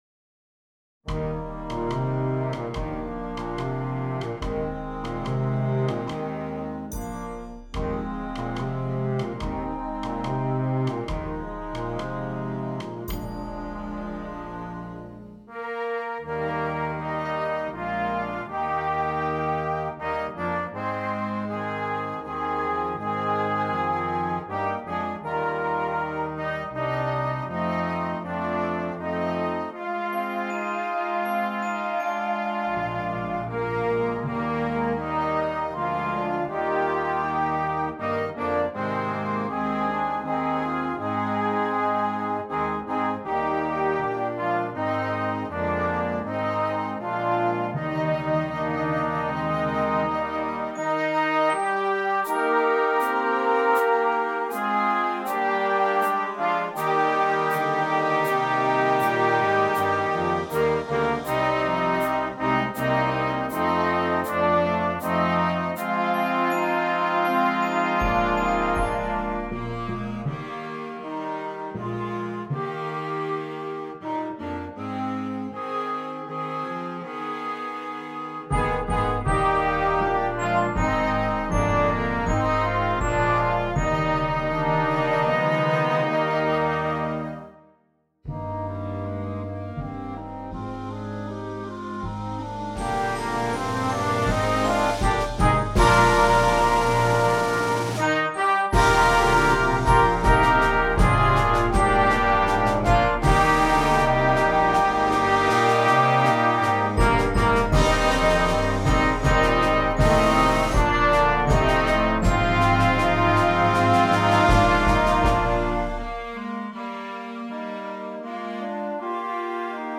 Concert Band
Traditional
cowboy song